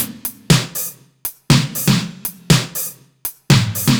Index of /musicradar/french-house-chillout-samples/120bpm/Beats
FHC_BeatC_120-02_NoKick.wav